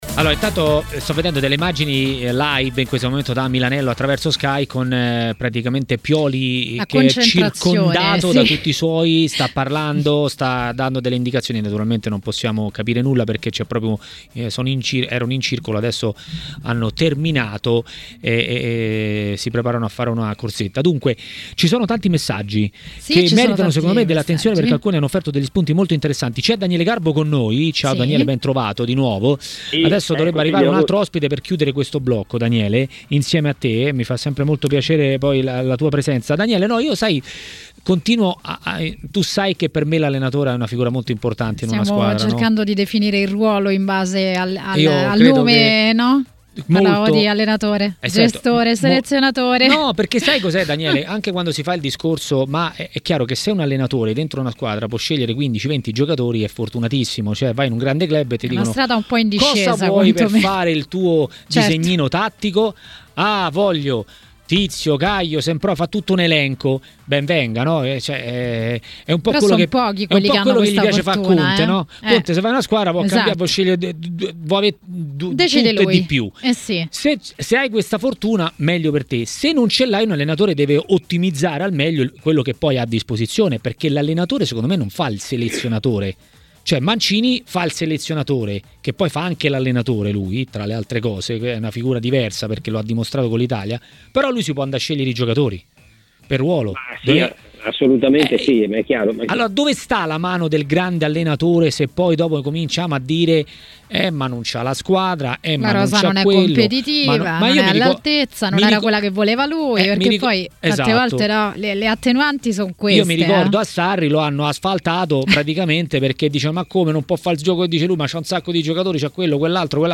L'ex calciatore Domenico Marocchino a Maracanà, nel pomeriggio di TMW Radio, ha parlato di Juve e non solo.